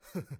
xys嘲弄3.wav 0:00.00 0:00.40 xys嘲弄3.wav WAV · 34 KB · 單聲道 (1ch) 下载文件 本站所有音效均采用 CC0 授权 ，可免费用于商业与个人项目，无需署名。
人声采集素材